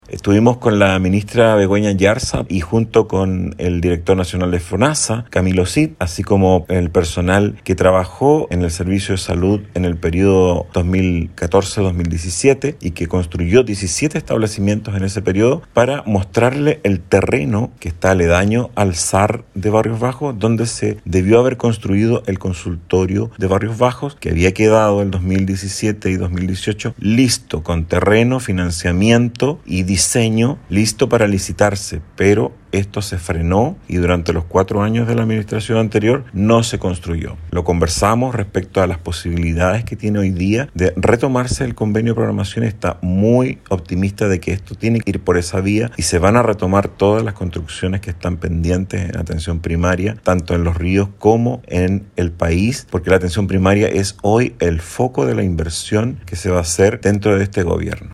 AUDIO-DIPUTADO-ROSAS.mp3